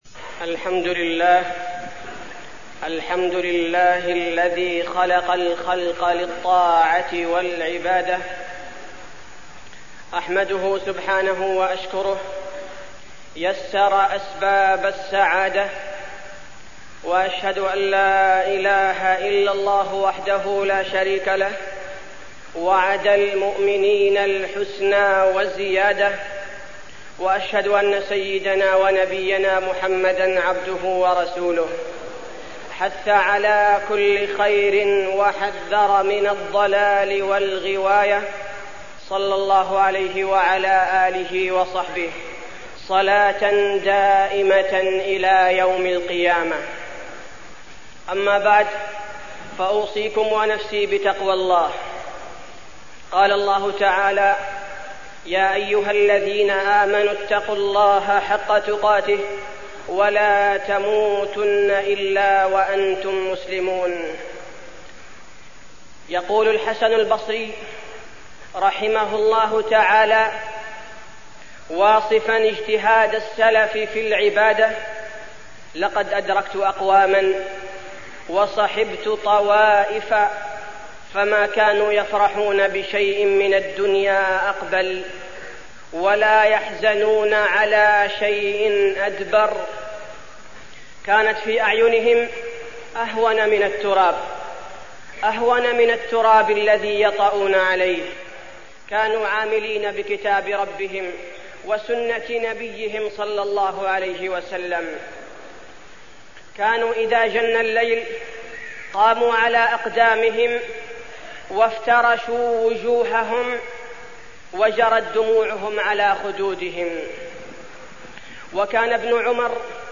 تاريخ النشر ٢١ رجب ١٤١٨ هـ المكان: المسجد النبوي الشيخ: فضيلة الشيخ عبدالباري الثبيتي فضيلة الشيخ عبدالباري الثبيتي العبودية لله عز وجل